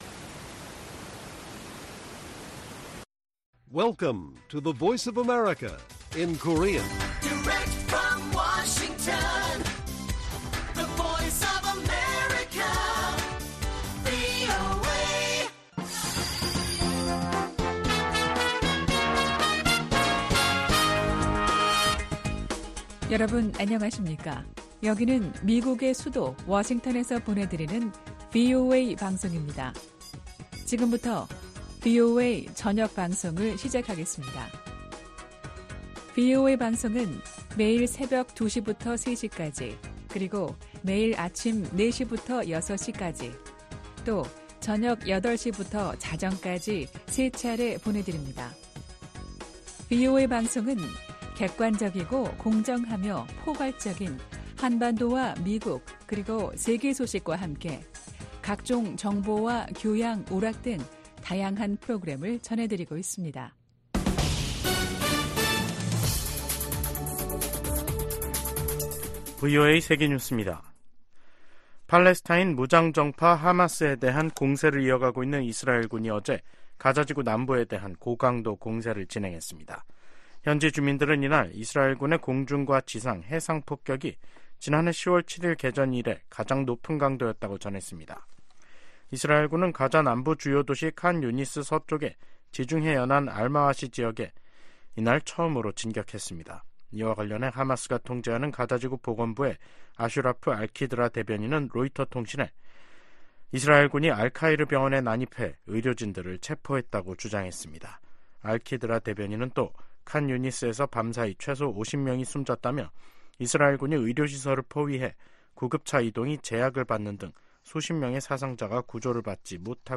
VOA 한국어 간판 뉴스 프로그램 '뉴스 투데이', 2024년 1월 23일 1부 방송입니다. 북한-러시아 군사협력은 역내 안정과 국제 비확산 체제를 약화시킨다고 유엔 주재 미국 차석대사가 지적했습니다. 백악관이 북한과 러시아의 무기 거래를 거론하며 우크라이나에 대한 지원의 필요성을 강조했습니다. 중국에 대한 보편적 정례 인권 검토(UPR)를 앞두고 유엔과 유럽연합(EU)이 탈북민 강제 북송 중단을 중국에 촉구했습니다.